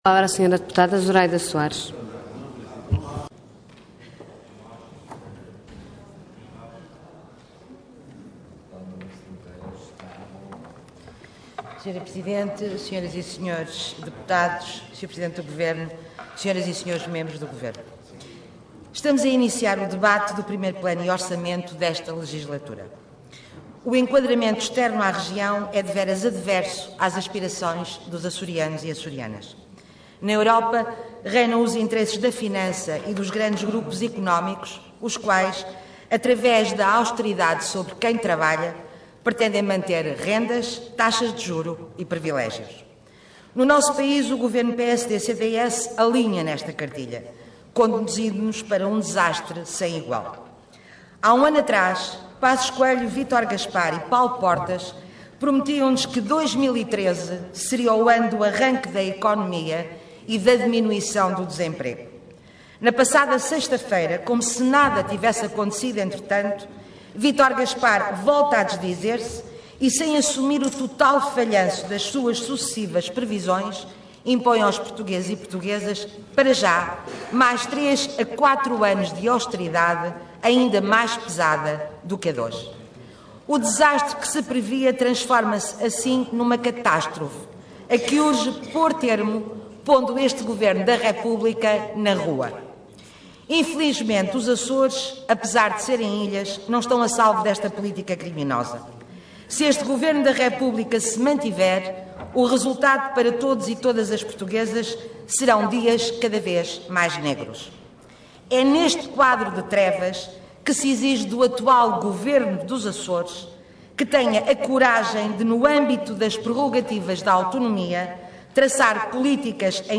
Intervenção Intervenção de Tribuna Orador Zuraida Soares Cargo Deputada Entidade BE